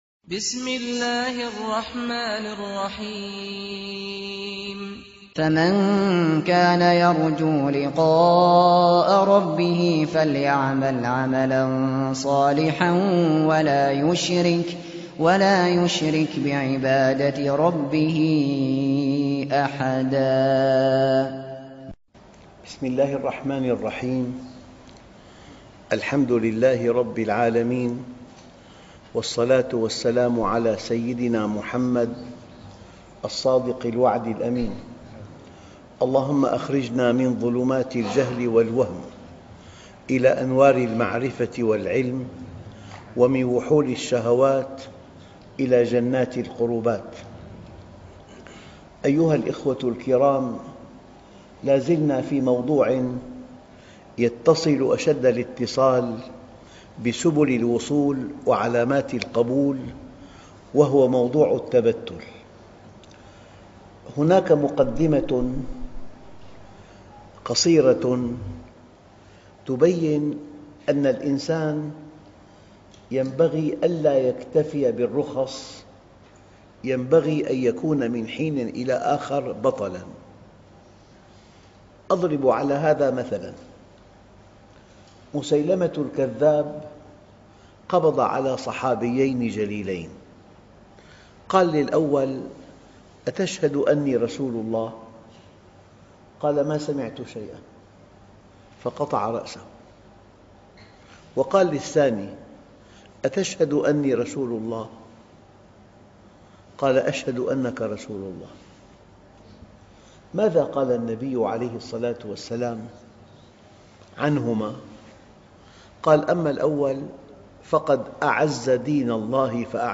الدرس (64-70) حال المتبتل مع الله - سبل الوصول وعلامات القبول - الشيخ محمد راتب النابلسي